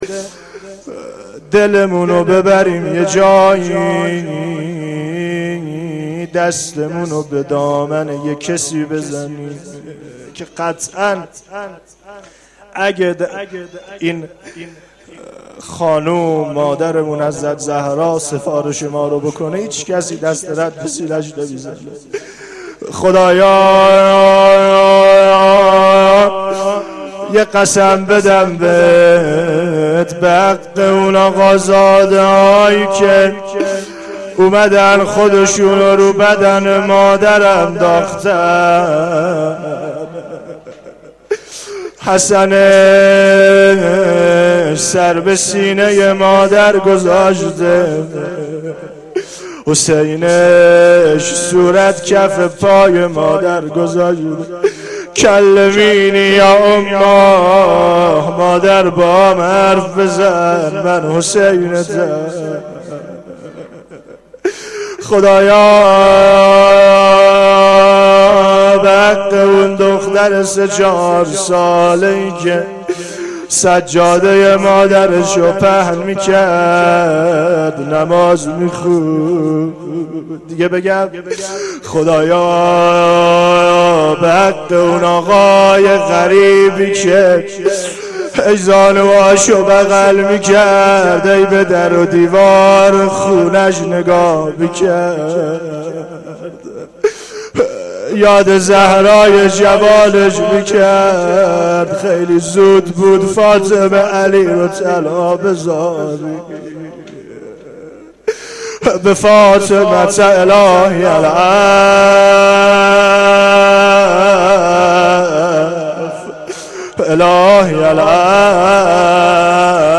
روضه3
روضه-1.mp3